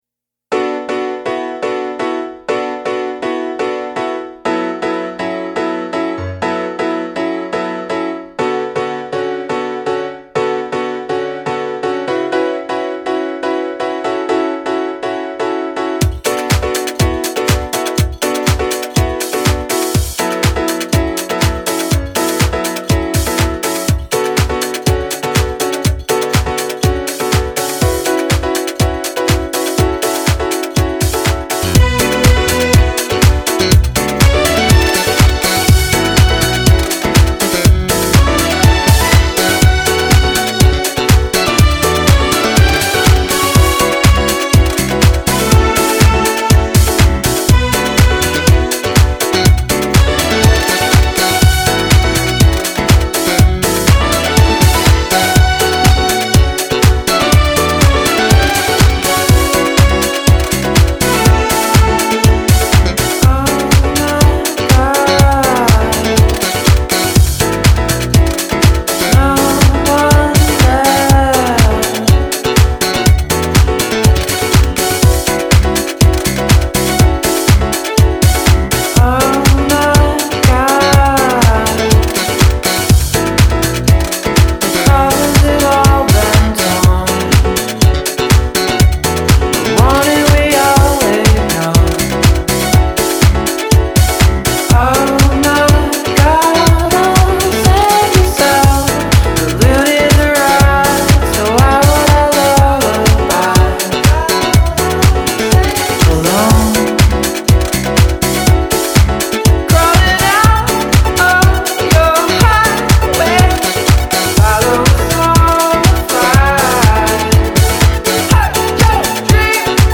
Italo Disco influenced treatment